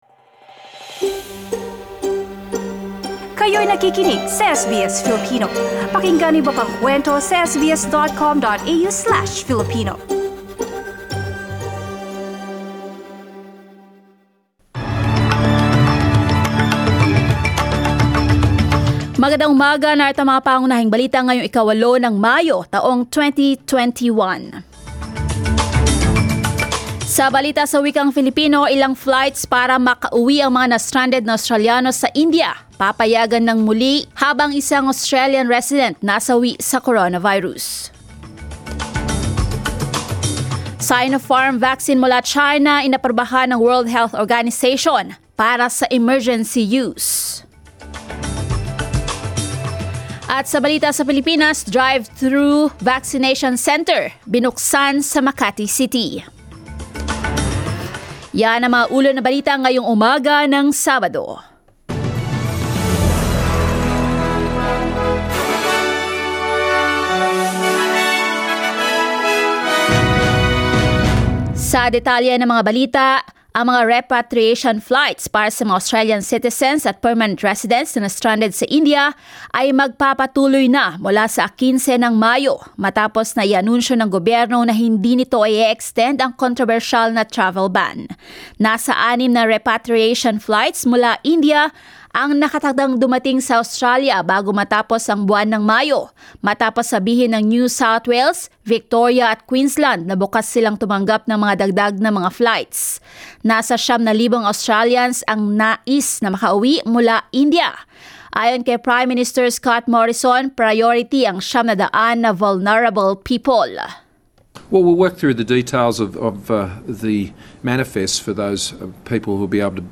SBS News in Filipino, Saturday 8 May